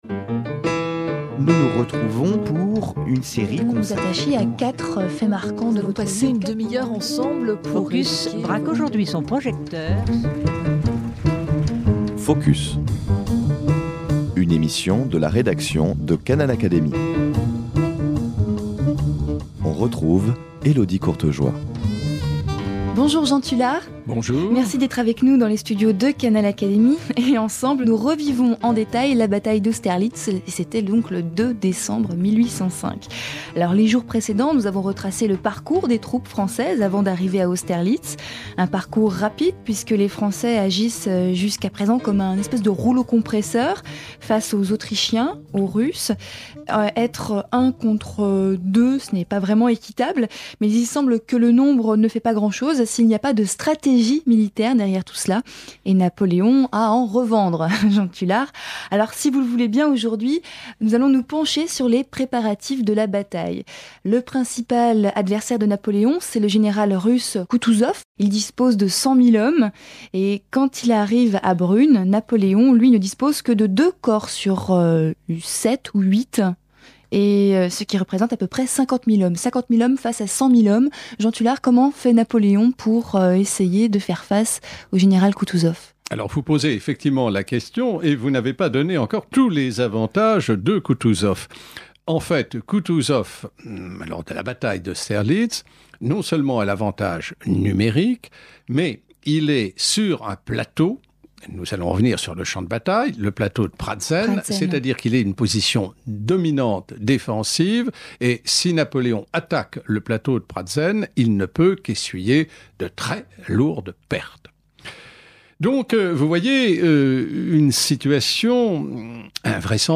La bataille d’Austerlitz, troisième volet de l’analyse de Jean Tulard, président de l’Académie des sciences morales et politiques (2005)